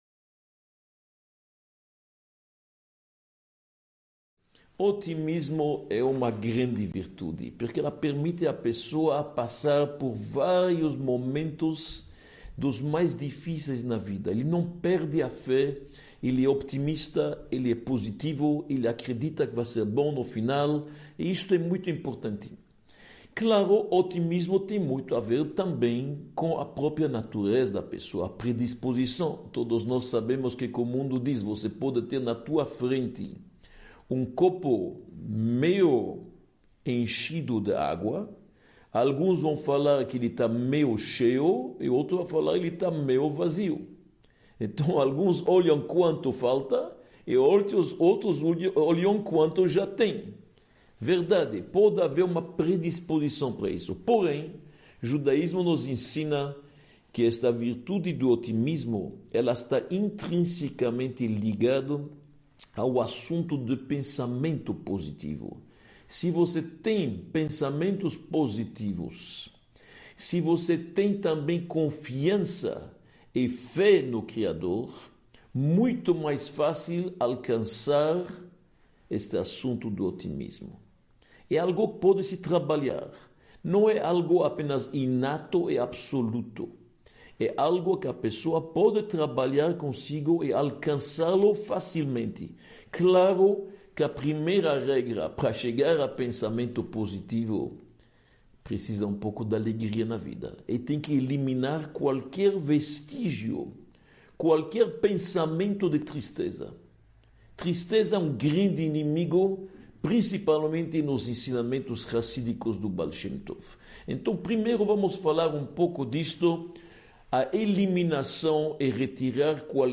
01 – Enxergando a vida com positivismo e otimismo | Comportamento e Atitudes – Aula 01 | Manual Judaico